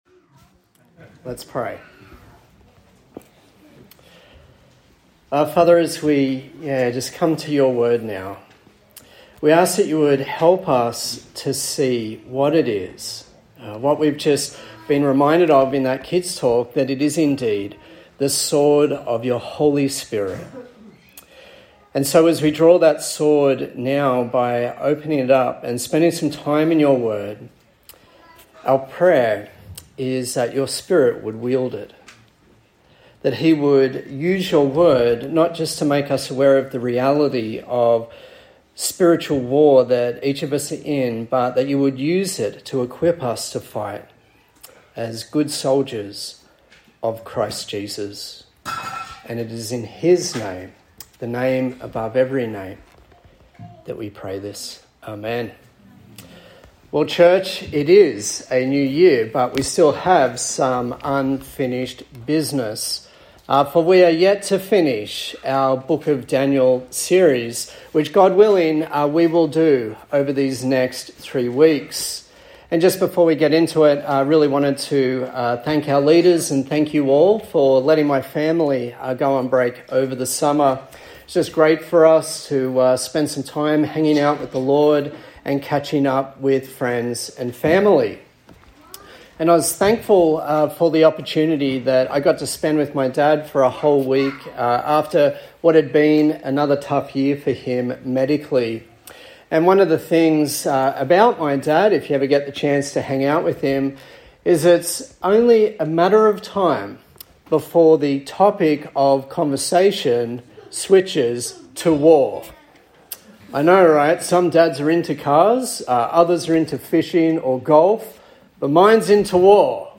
A sermon in the series on the book of Daniel
Service Type: Sunday Service